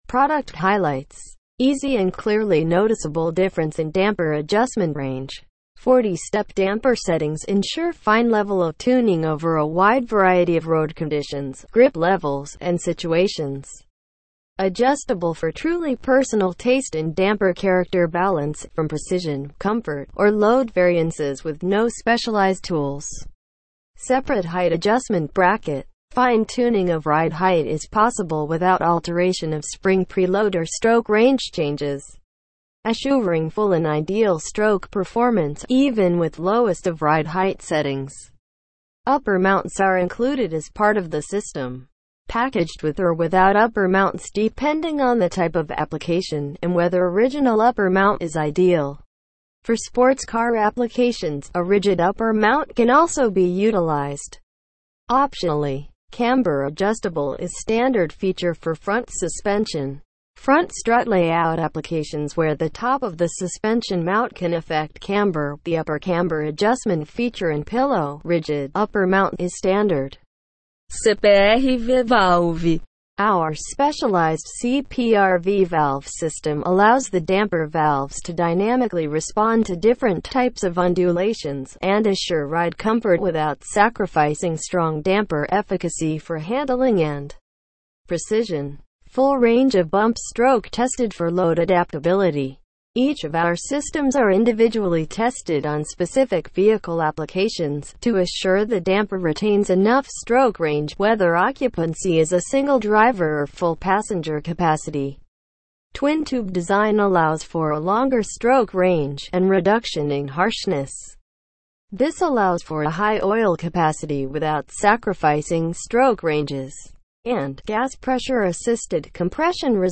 TEXT TO SPEECH